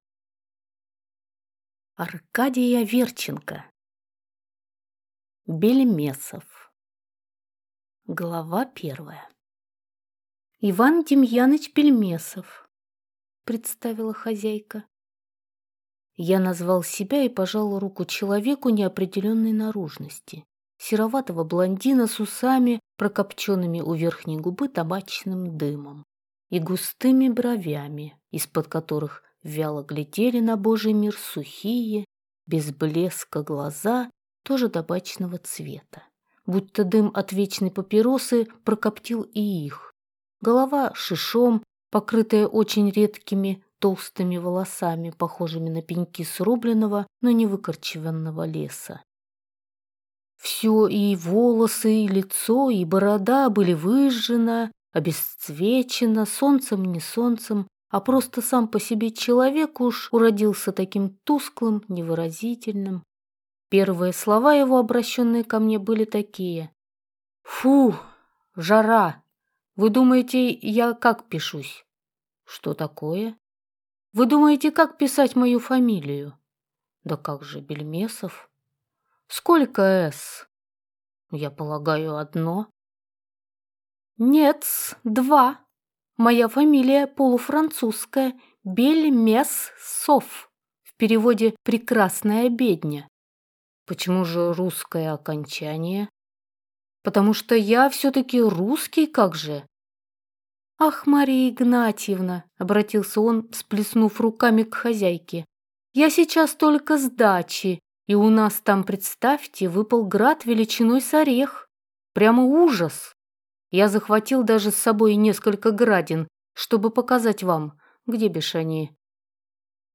Аудиокнига Бельмесов | Библиотека аудиокниг